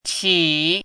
注音： ㄑㄧˇ
qi3.mp3